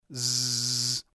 y otro sonido en que sí vibran las cuerdas vocales [¸]